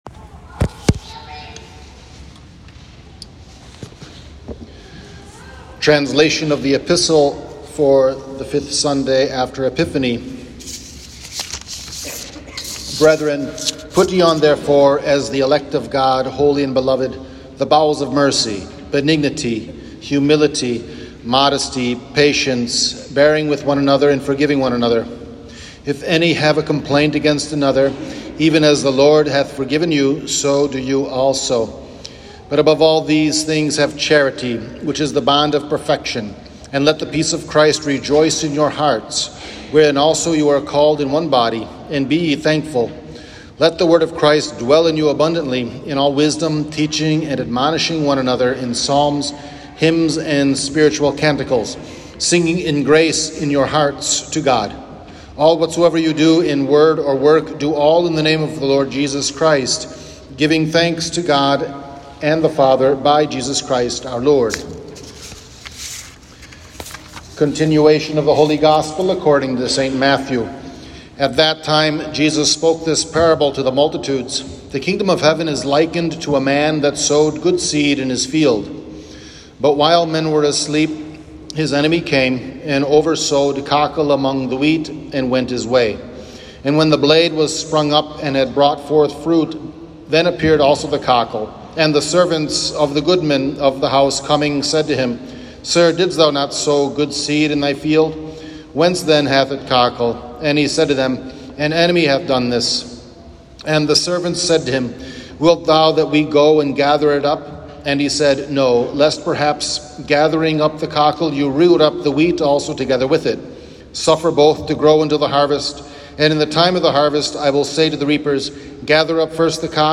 Transcription of Homily